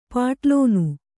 ♪ pāṭlōnu